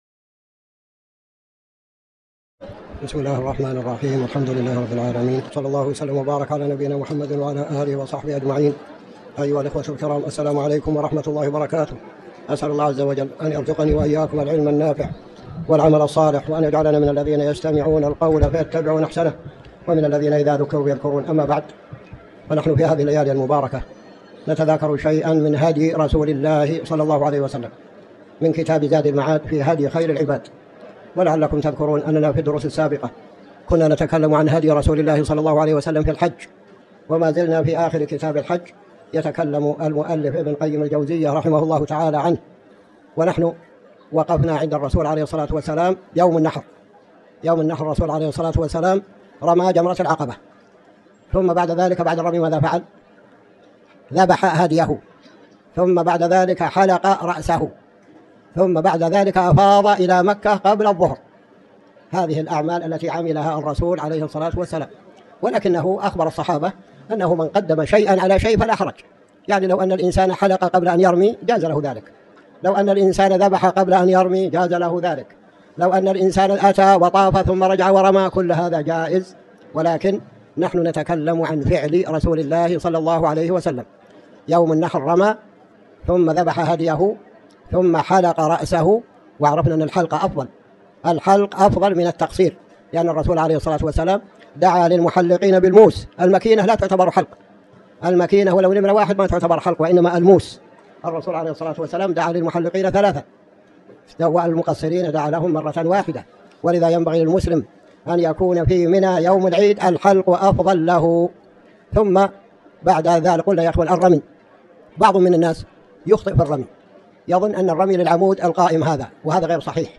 تاريخ النشر ١٣ جمادى الأولى ١٤٤٠ هـ المكان: المسجد الحرام الشيخ